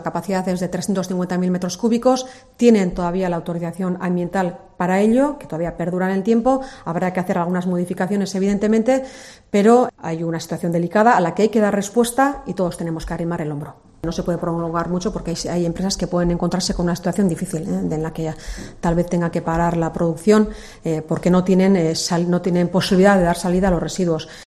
Eider Mendoza, portavoz foral de Gipuzkoa